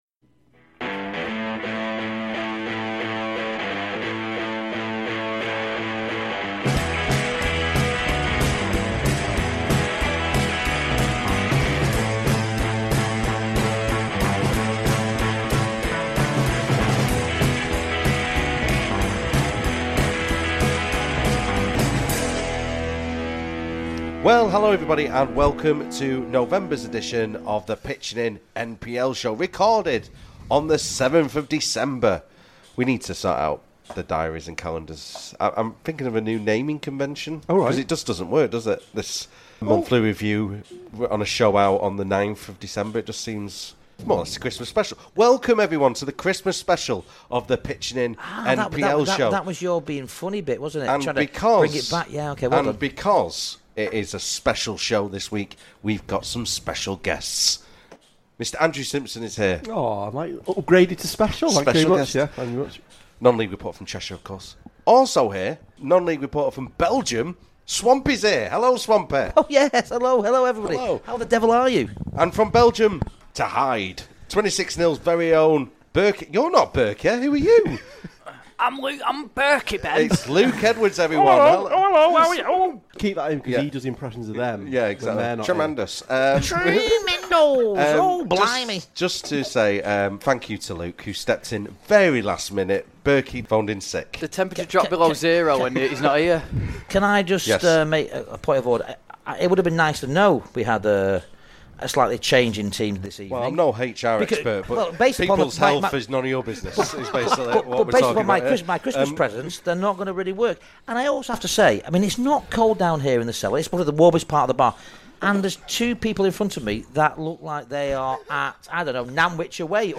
This programme was recorded at a virtual Heaton Hops on Wednesday 7th December 2022.